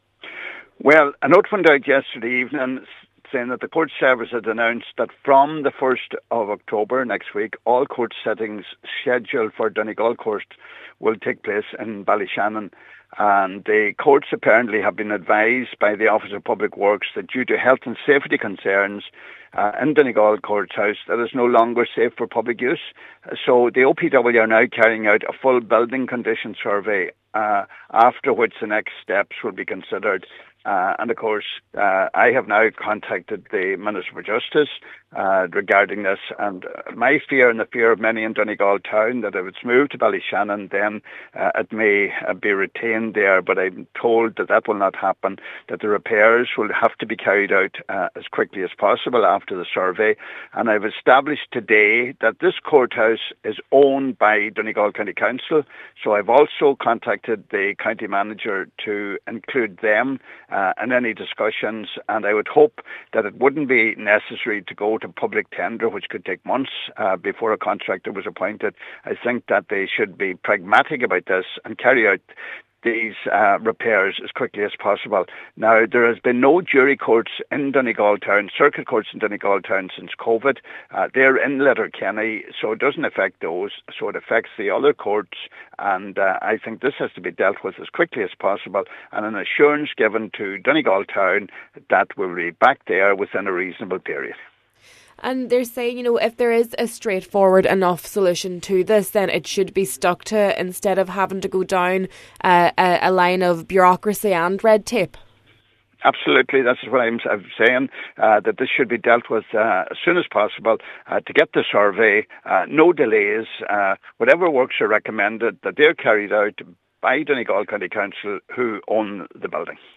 The Donegal Deputy says the process cannot become wrapped up in red tape: